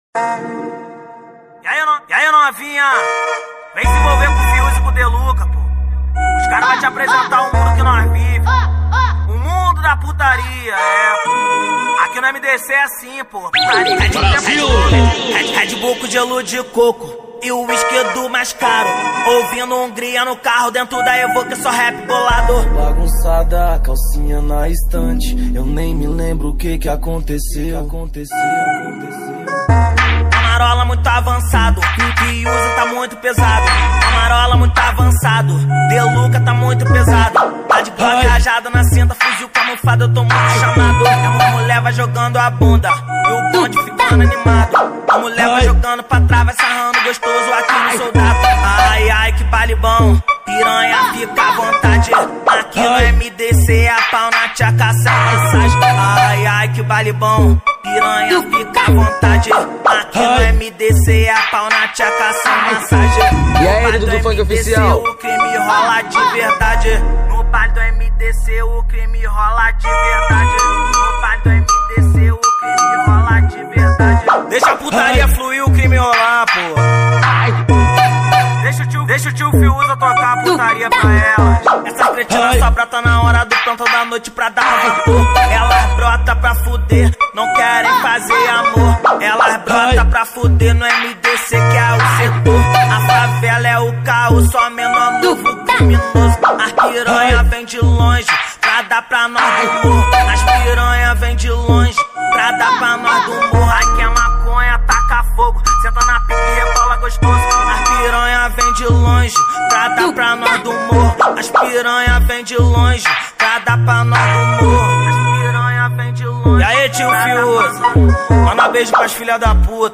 2024-09-22 10:04:42 Gênero: Funk Views